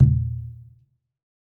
PERC - NOTHINGLESS.wav